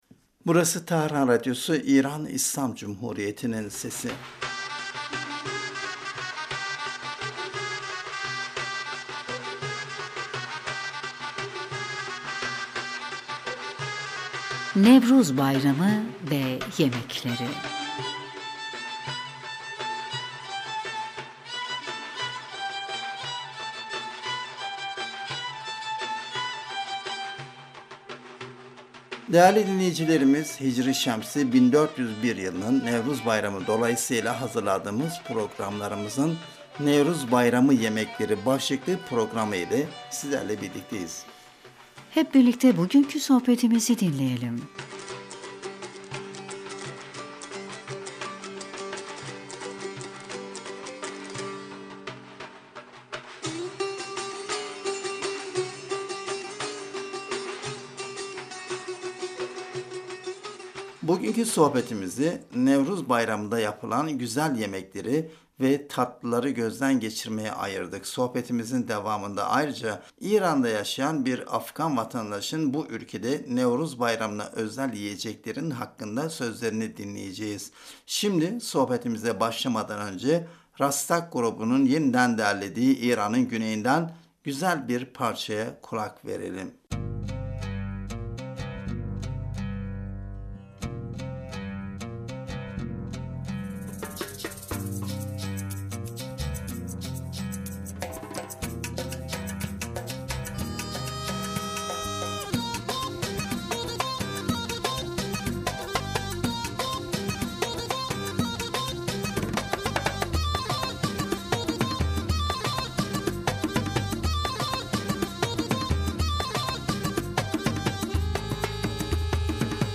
Sohbetimizin devamında ayrıca İran’da yaşayan bir Afganistan vatandaşının bu ülkede Nevruz bayramına özel yiyeceklerin hakkındaki sözlerini dinleyeceğiz.
İran’ın Güney yöresinin müziği bu bölgenin sıcak ikliminden etkilenerek oldukça coşkulu, güçlü ve hararetli bir müziktir.